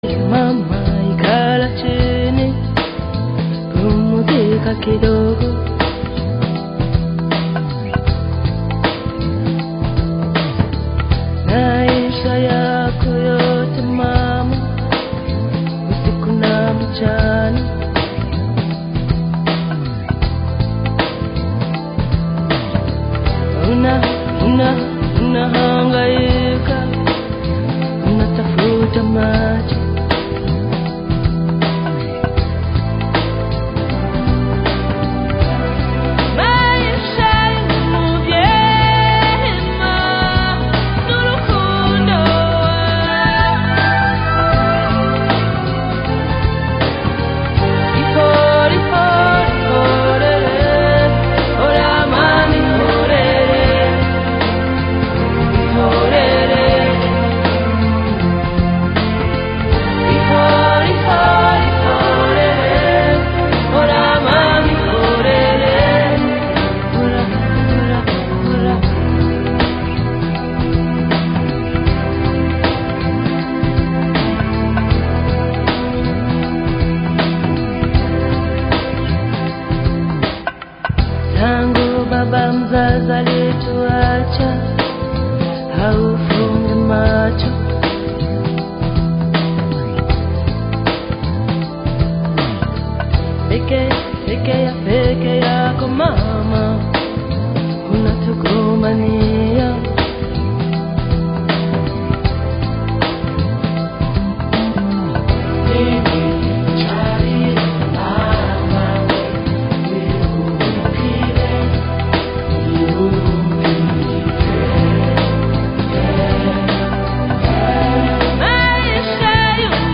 Genre: Soundtrack , Variety , Children's